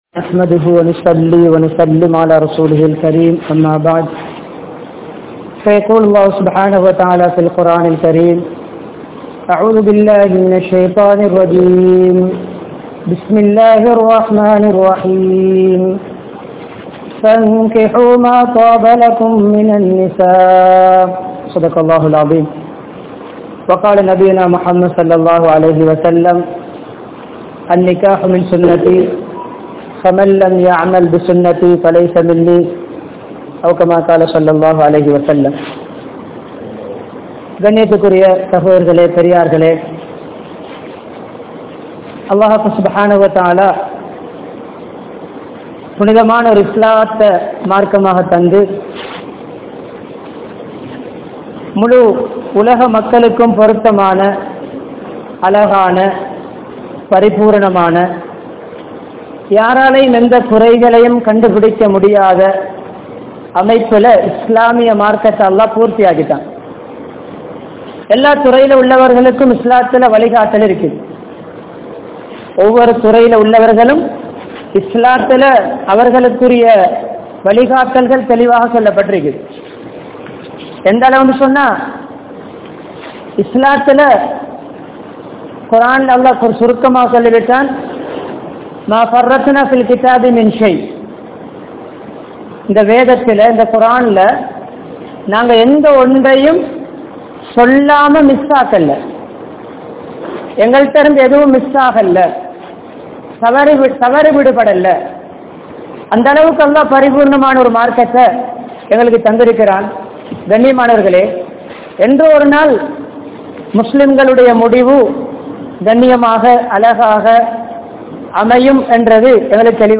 Poiyaana Kudumba Vaalkai Vaalaatheerhal (பொய்யான குடும்ப வாழ்க்கை வாழாதீர்கள்) | Audio Bayans | All Ceylon Muslim Youth Community | Addalaichenai
Colombo 15, Mattakuliya, Mutwal Jumua Masjidh